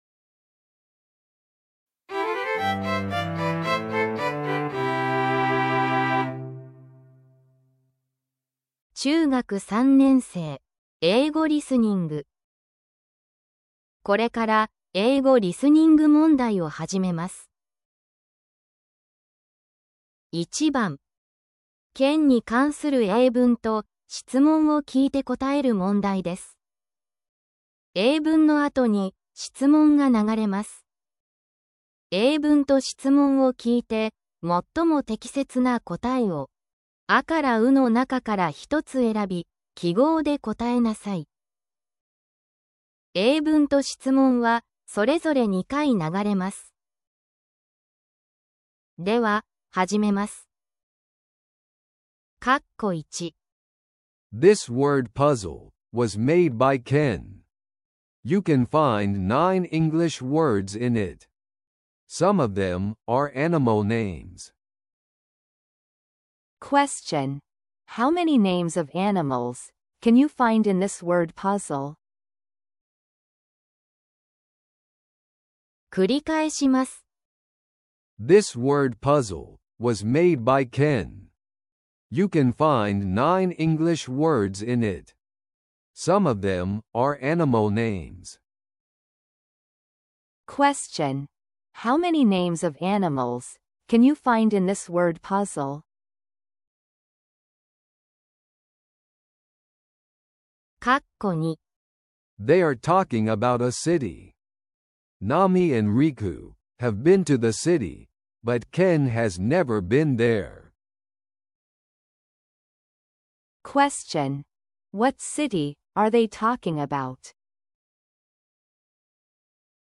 中学３年生 英語リスニング問題⑩
～ PDF：ふくろうドリル 中学３年生⑩ 問題＆解答用紙 解答＆放送原稿 PDF：ふくろうドリル 中学３年生⑩ 解答＆放送原稿 ※当サイトの音源は、AI音声（音読さん）にて作成されています。